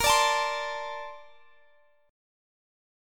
BbM9 Chord
Listen to BbM9 strummed